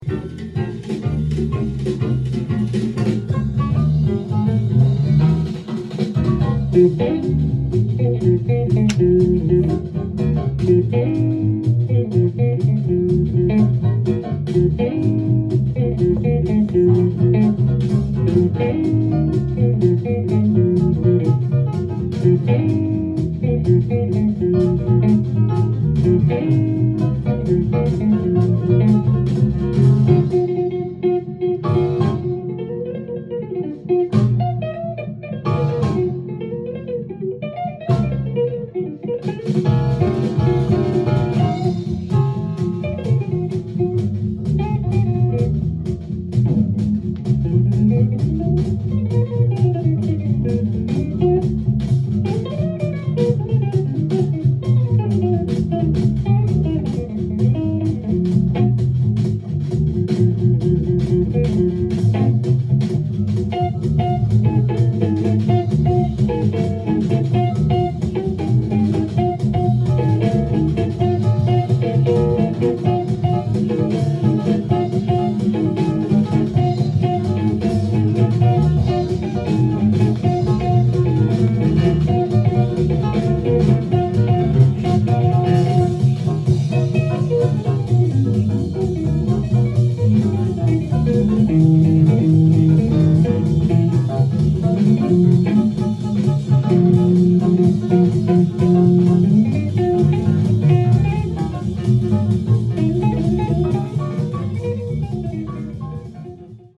ジャンル：JAZZ-ALL
店頭で録音した音源の為、多少の外部音や音質の悪さはございますが、サンプルとしてご視聴ください。
クァルテットで演奏されるこのアルバムは、再会を喜び合って見事な演奏を披露。